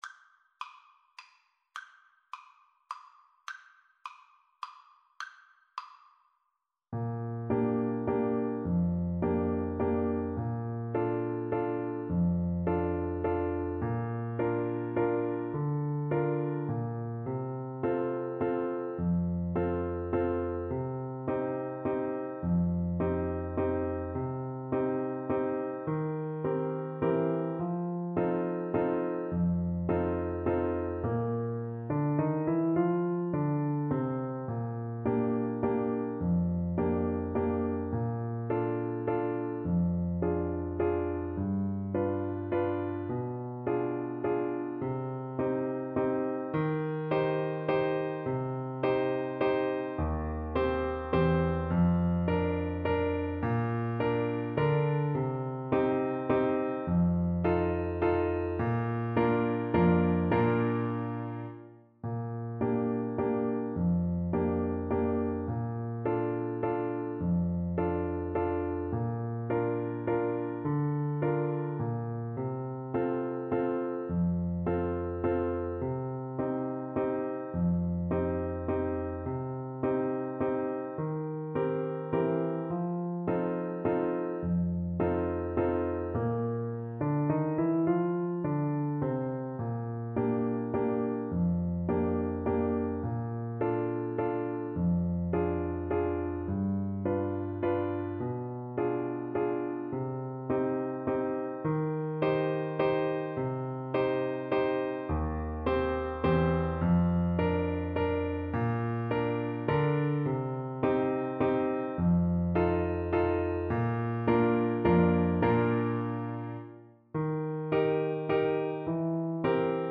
Free Sheet music for Clarinet
Clarinet
Bb major (Sounding Pitch) C major (Clarinet in Bb) (View more Bb major Music for Clarinet )
3/4 (View more 3/4 Music)
One in a bar . = c. 52
Classical (View more Classical Clarinet Music)
world (View more world Clarinet Music)